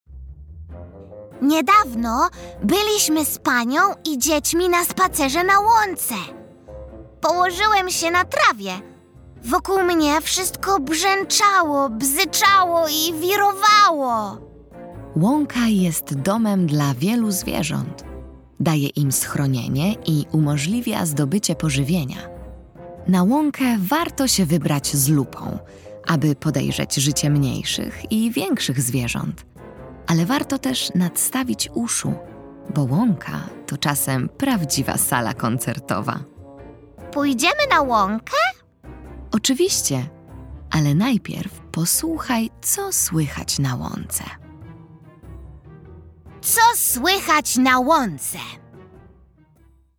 Co słychać na łące - rozmowa - EDURANGA